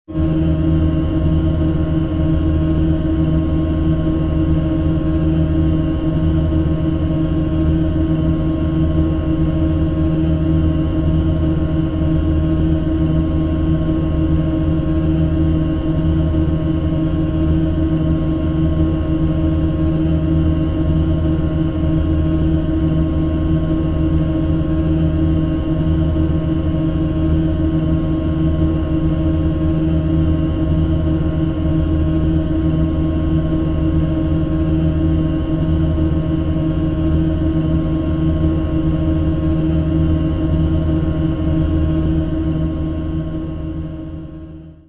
Blood Analyzer; Machine On, Run With Clicks, Air Chuffs And Whine